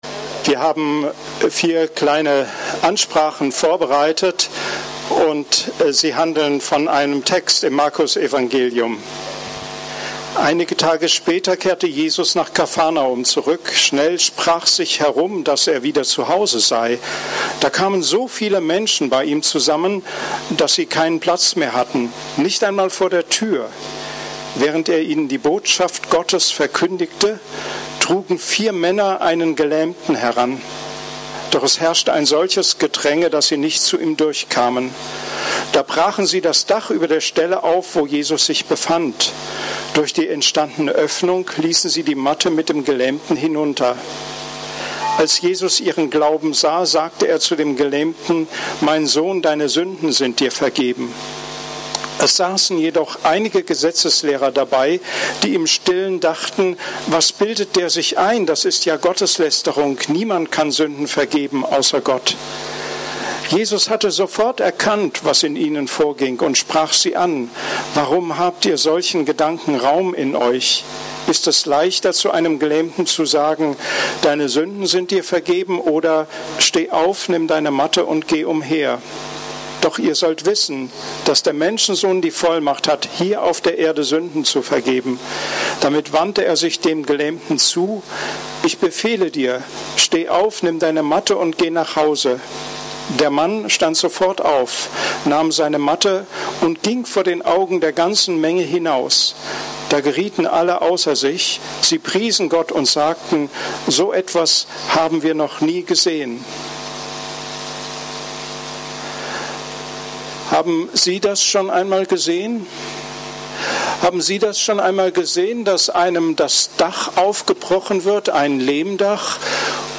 Predigt vom 12. Mai 2019